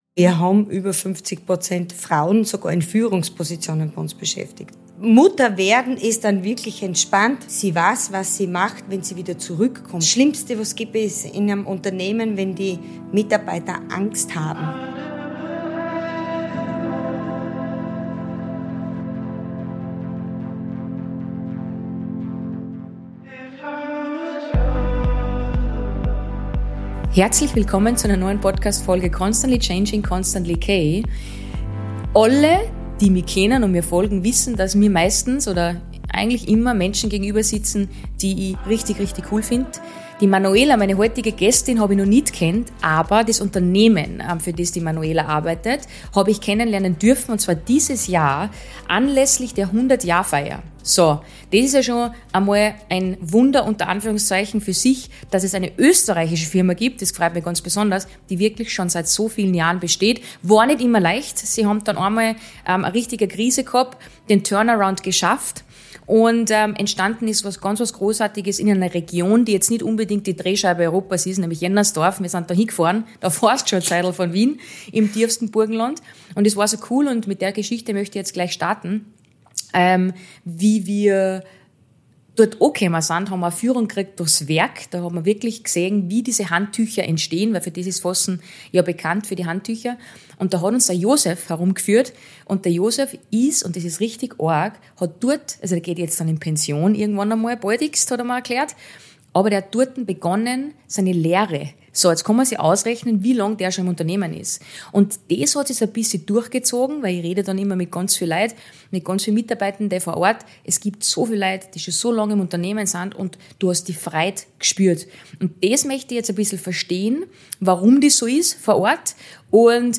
Ein Gespräch, das mir wieder einmal gezeigt hat, wie viel möglich ist, wenn Menschen im Unternehmen wirklich zählen. Wir reden über Führung mit Haltung, über Vertrauen statt Angst, über Arbeiten am Land, Frauenkarrieren, Karenz ohne Stillstand und darüber, warum über 50 % Frauen – auch in Führungspositionen – kein Zufall sind.